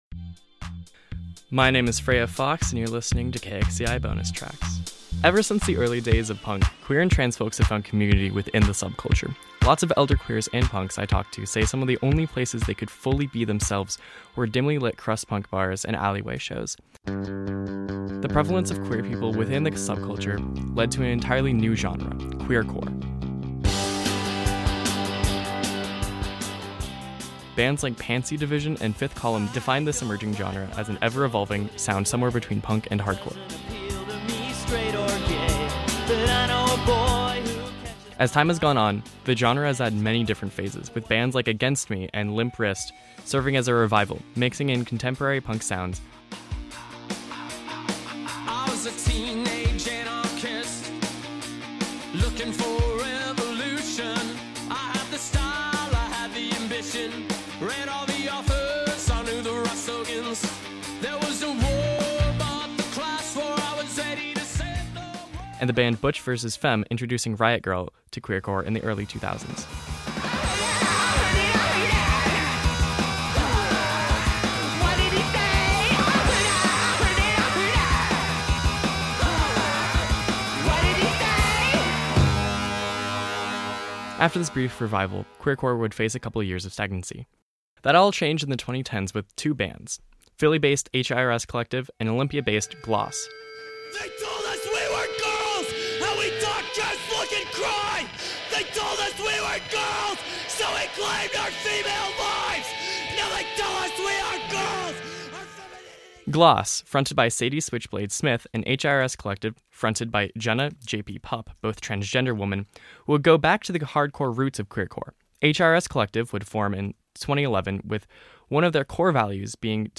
Bonus Tracks,   KXCI,   queercore,